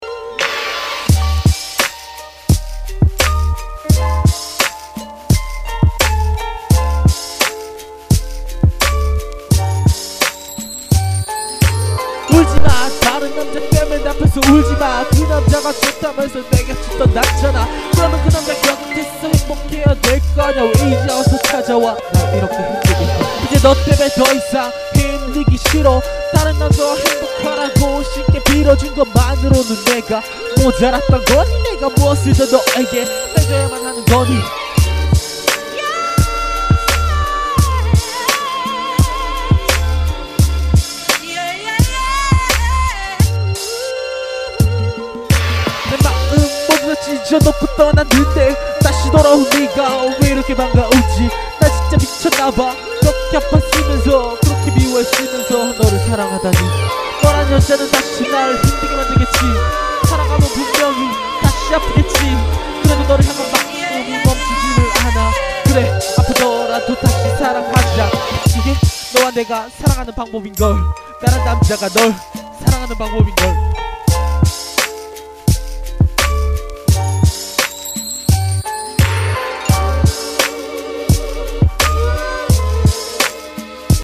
녹음이 서툴다보니 영 구리게 뽑히네요ㅠ.ㅠ